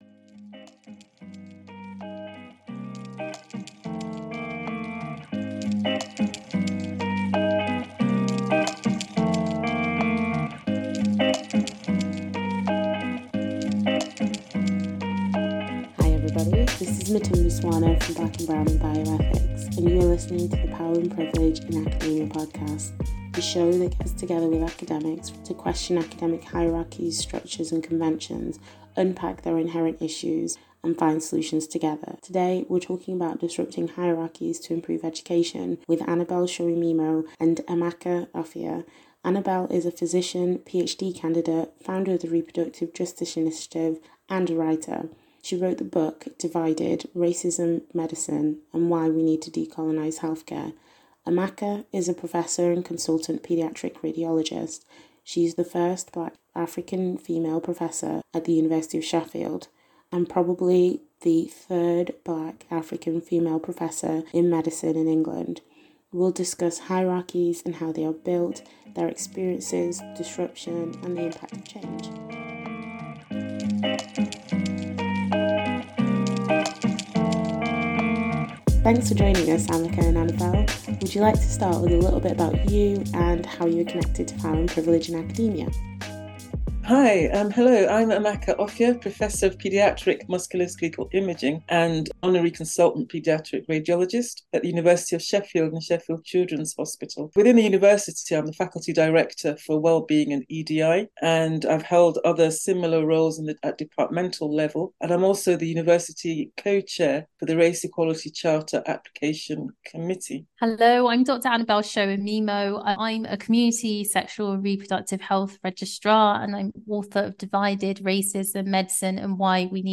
cross-continental conversation